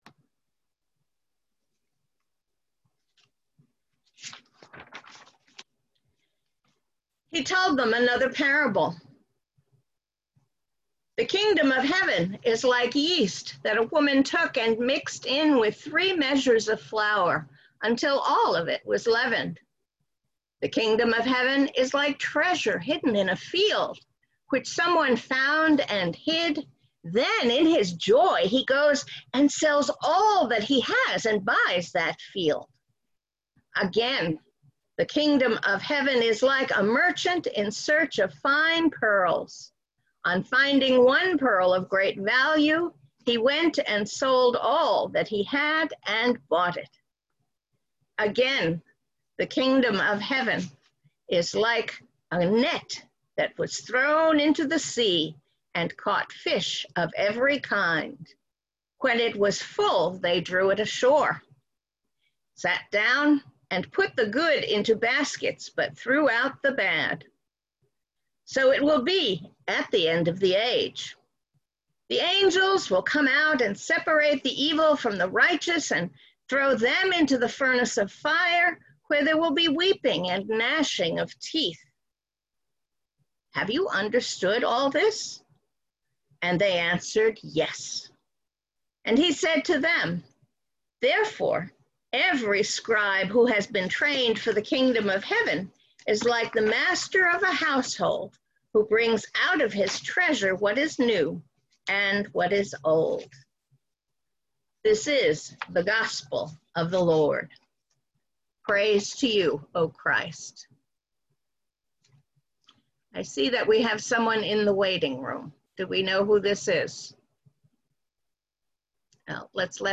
Sermons | Lutheran Church of the Epiphany and Iglesia Luterana de la Epifania